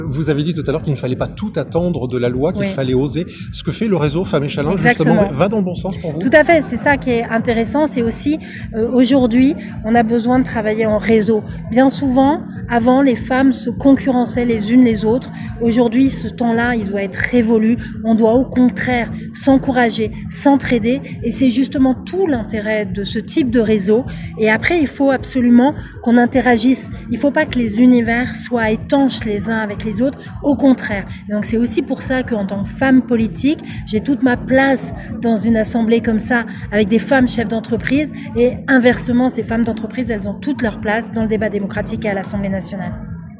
La 7e édition du forum Femmes & Challenges était organisée hier au Zénith de Rouen.
Yaël Braun-Pivet, présidente de l'Assemblée nationale